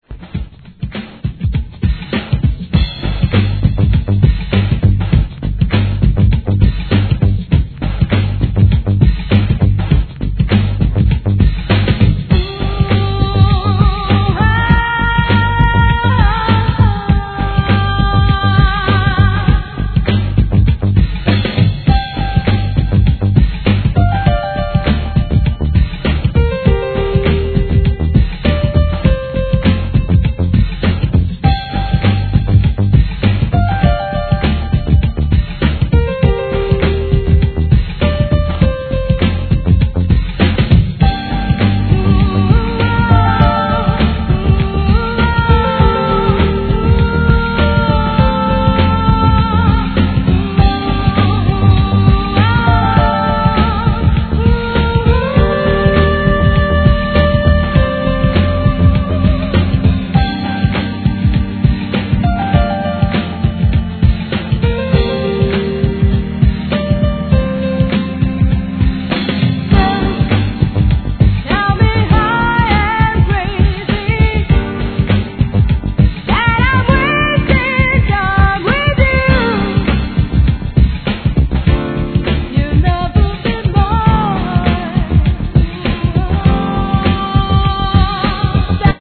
HIP HOP/R&B
CLUB MIX